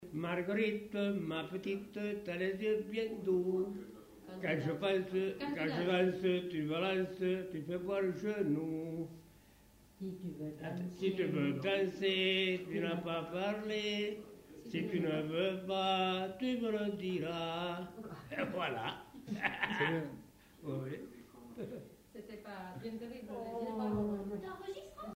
Aire culturelle : Savès
Lieu : Pavie
Genre : chant
Effectif : 1
Type de voix : voix d'homme
Production du son : chanté
Danse : varsovienne